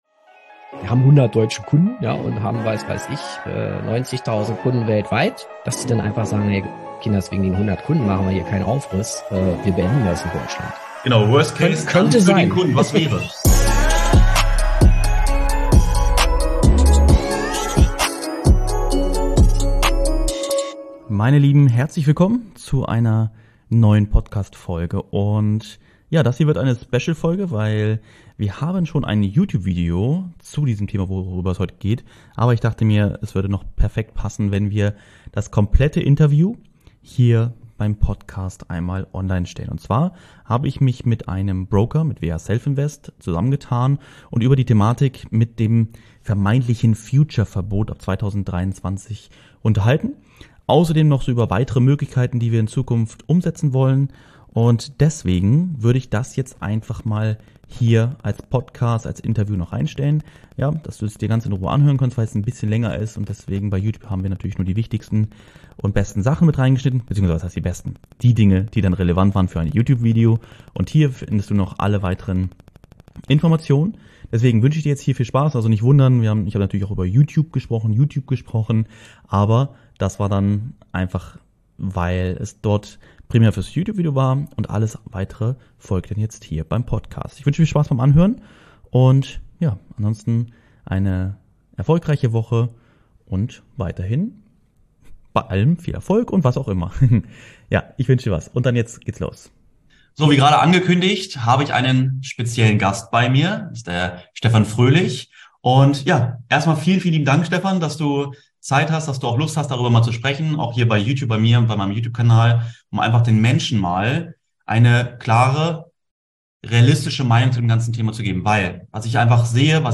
Ein Broker bezieht im diesem Interview Stellung und klärt darüber auf was es mit dem "Future Verbot" auf sich hat.
Interview mit einem Broker